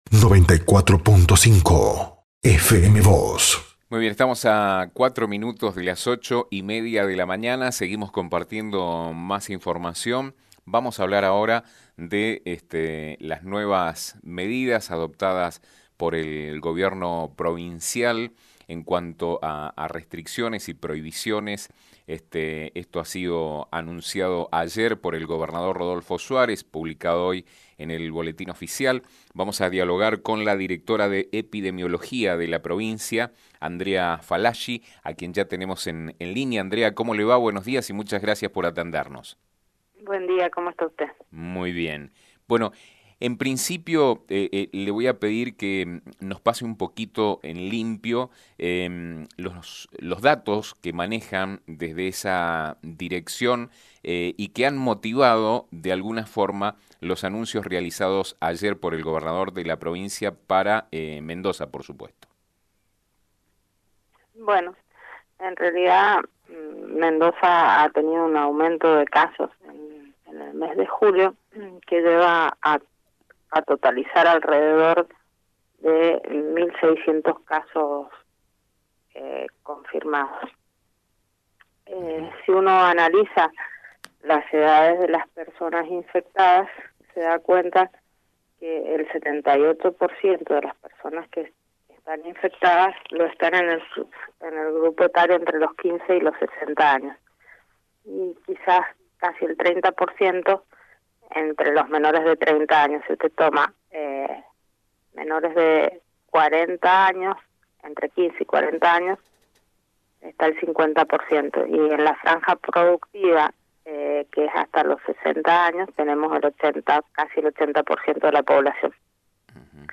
Su asunción en el Gobierno provincial no fue quizá en un buen momento, ya que el mes de julio ha marcado un alto incremento de casos positivos de Covid-19. Sobre este y otros temas, la funcionaria dialogó con FM Vos (94.5) y Diario San Rafael.